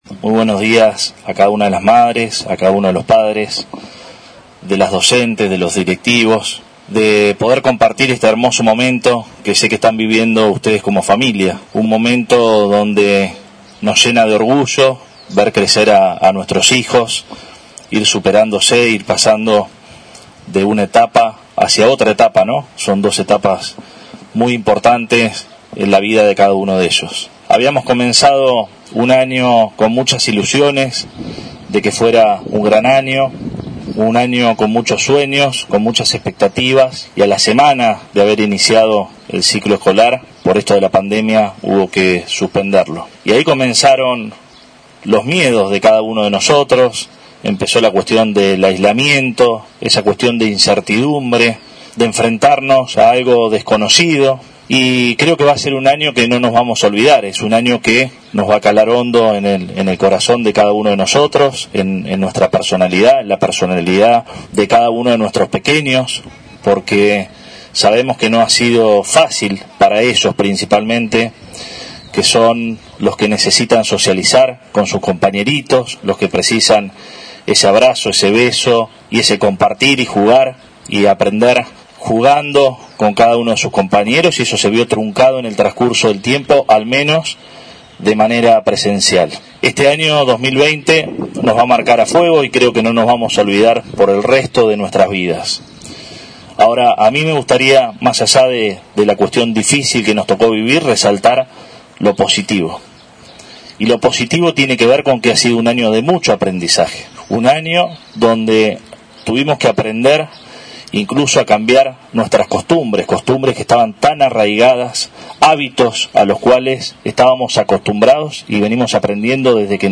Con una ceremonia al aire libre y respetando el distanciamiento social, se llevó a cabo el acto de colación de uno de los jardines municipales, con la presencia del intendente municipal.
Con la presencia del intendente municipal, Arturo Rojas, y bajo estrictos protocolos, se realizó el acto de fin de ciclo del Jardín Municipal Lassalle, que tuvo lugar en el Complejo Necopesca, al aire libre.